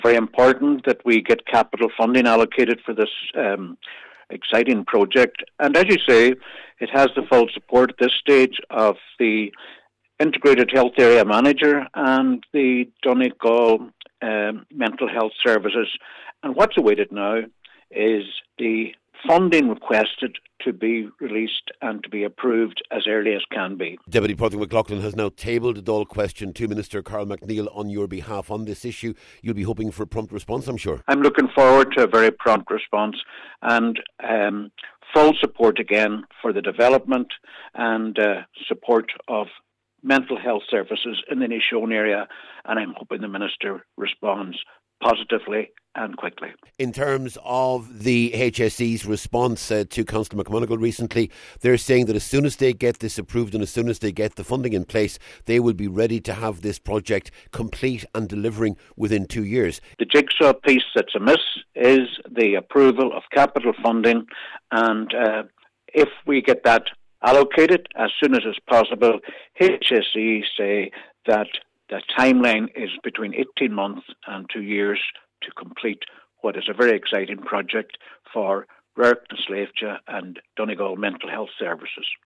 Cllr Albert Doherty says what’s needed now is a swift response from government……….